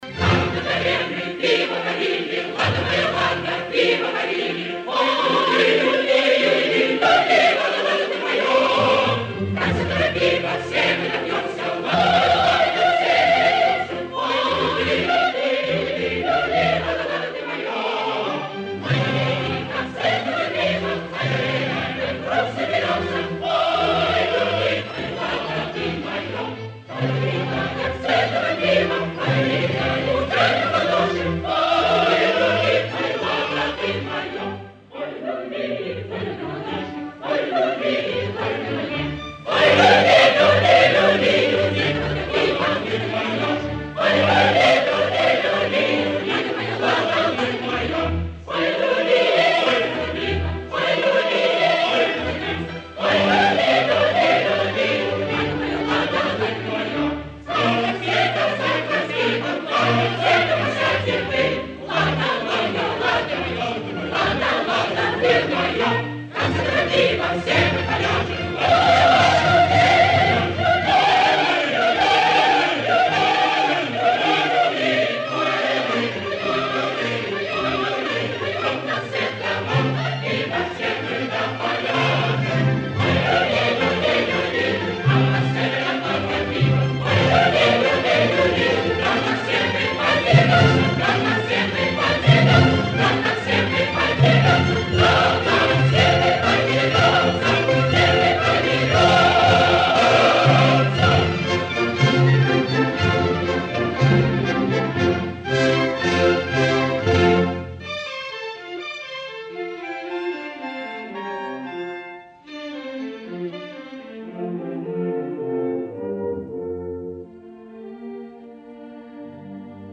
Русалка 1 д_ Хор Как на горе мы пиво варили.mp3